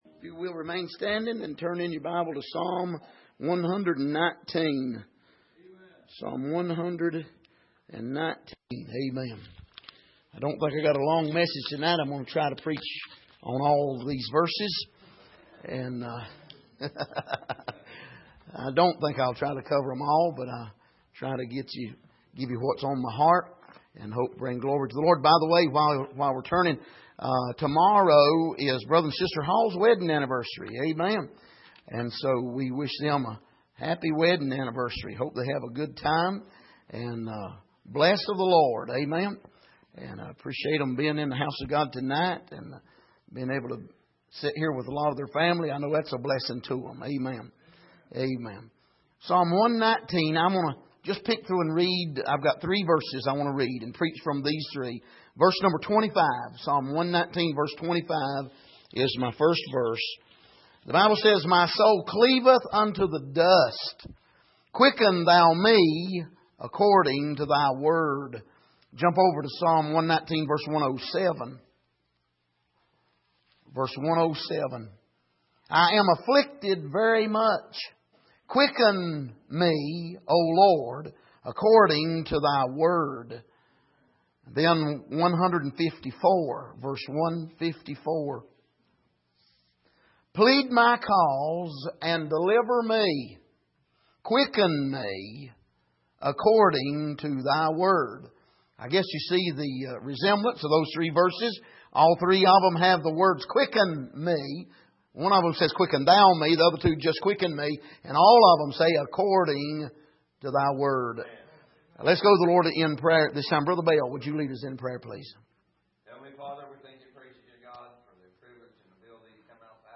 Miscellaneous Passage: Psalm 119:25 Service: Midweek The Revival We Need « Where Is The God of Judgment?